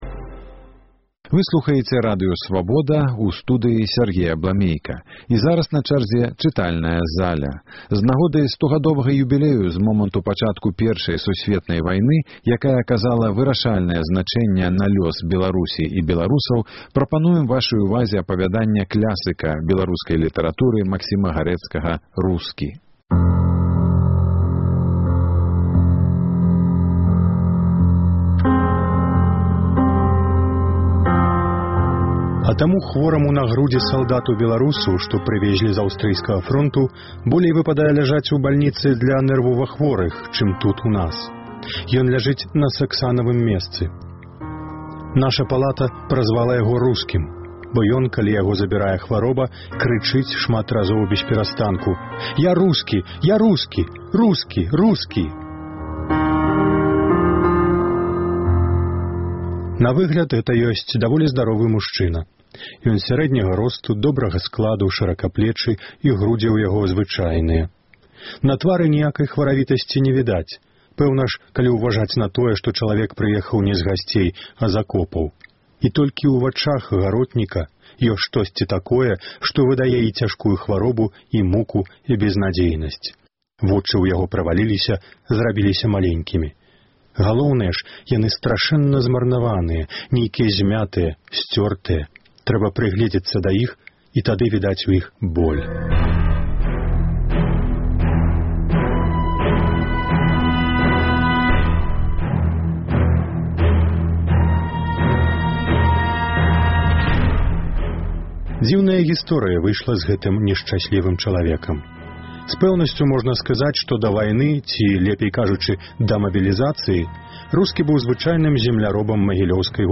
Апавяданьне клясыка беларускай літаратуры чытае